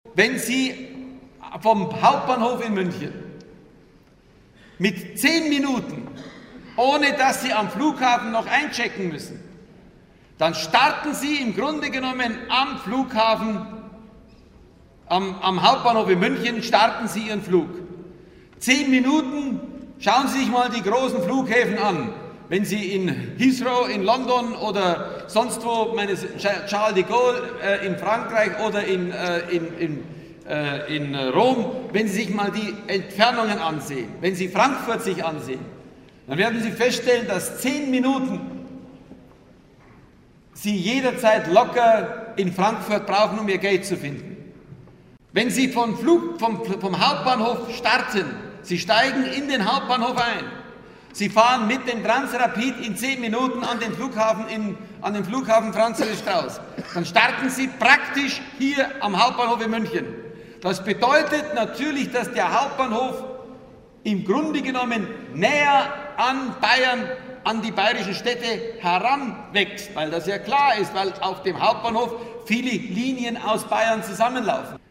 Nun hat sich der große Kommunikator mal wieder eine Rede geleistet, deren Inhalt schon beim Zuhören schlimme Kopfschmerzen erzeugt und die an Wirrheit kaum zu überbieten ist.
Hier findet Ihr besagte Rede